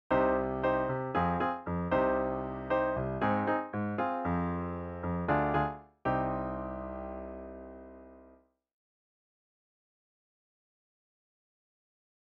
I’ve included a sample sound file to show how you might use the chords, but by all means experiment and use your imagination.
1. Cm  Fm  Cm  Ab  Fm  Ddim  C [